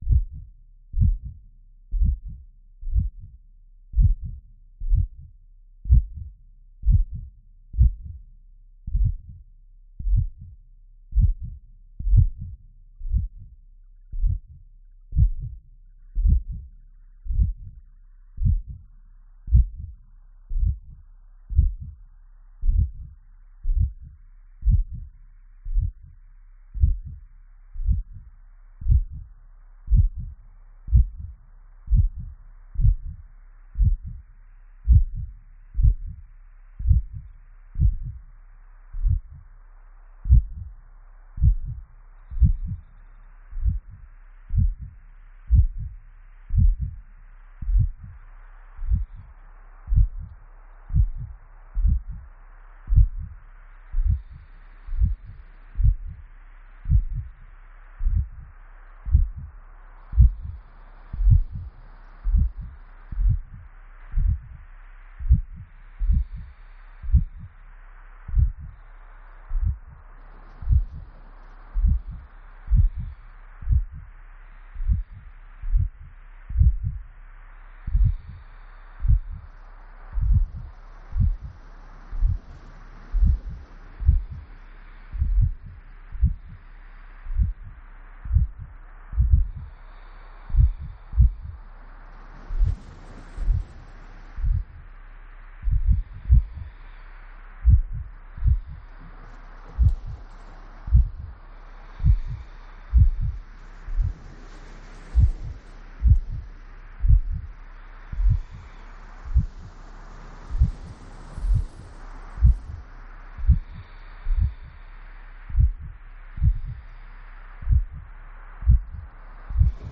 The murmurings of heartbeat and rhythmic breath are laid as sustenance for bodily grounding. The undulating crash of wave and wind invokes the seascape solace of Skellig Michael. From this corporeal sound environment rises the otherworldly vocalization of intention in Irish, language of body and landscape activation: Isteach, Amach/ In, Out.
15 minute meditation